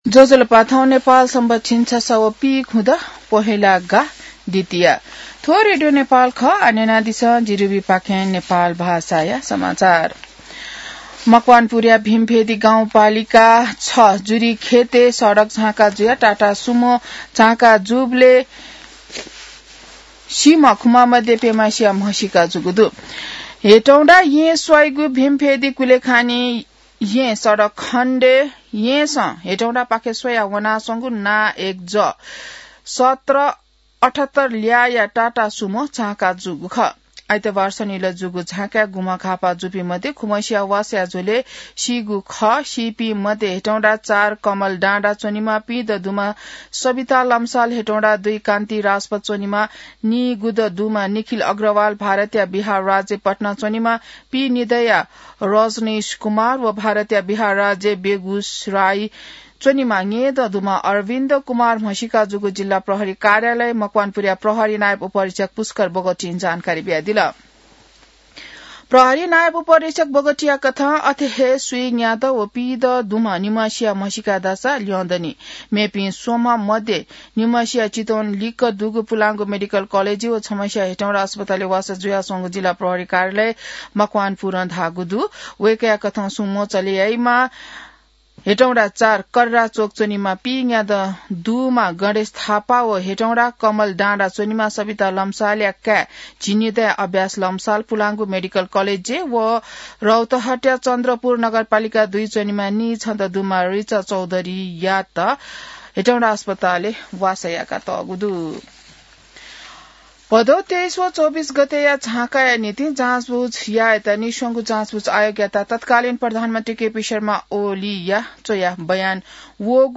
नेपाल भाषामा समाचार : २१ पुष , २०८२